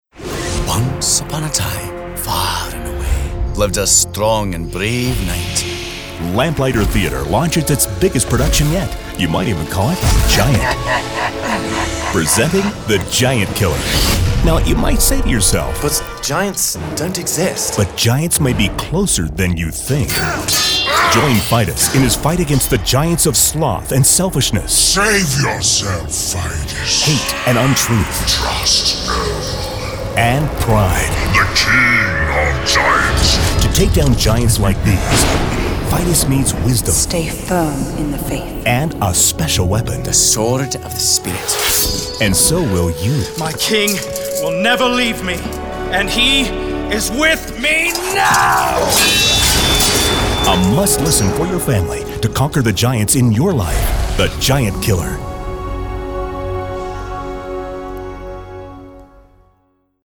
My husband said this audio theater is his new favorite.
Giant Killer Wonderful characters – well done – Though the giants did get a little hard to understand at times (had to repeat their segments).
Excellent audio quality and story.